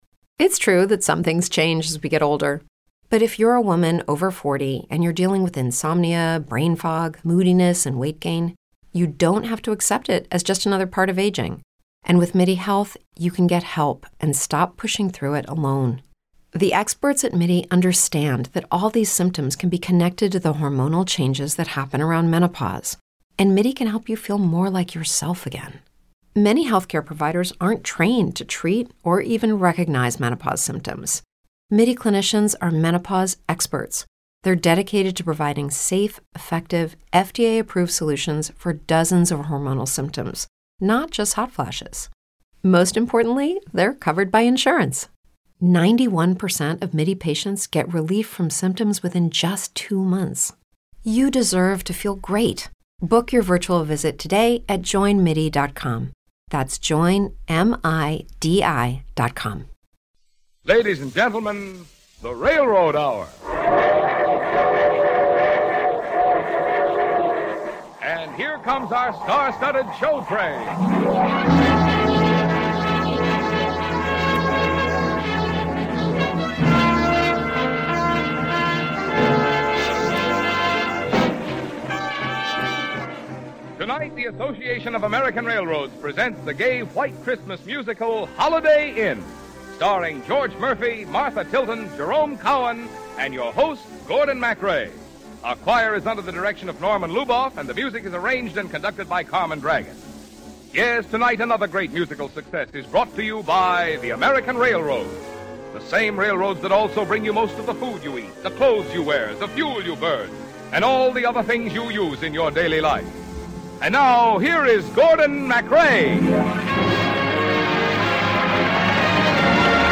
radio series that aired musical dramas and comedies